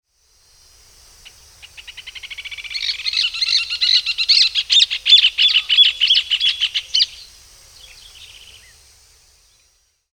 Furnarius rufus
Aprecie o canto do
João-de-barro
joaodebarro.mp3